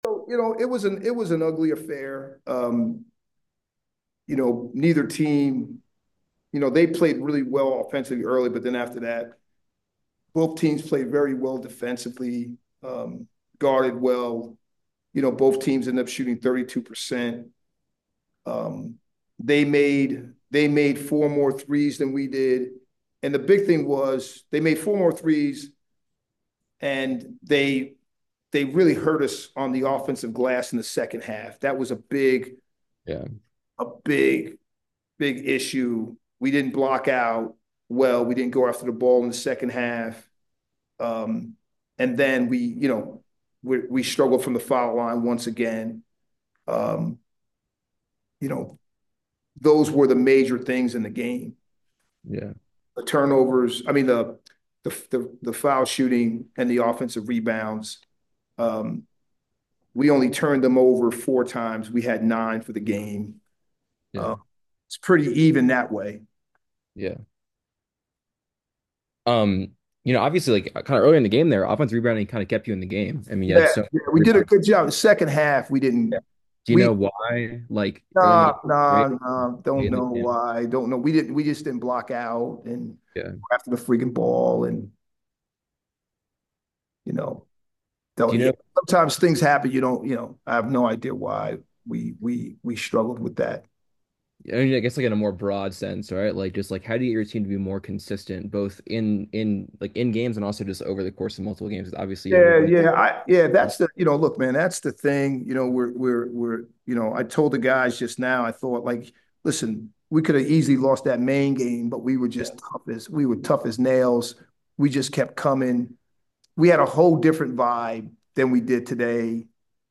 Lafayette Postgame Interview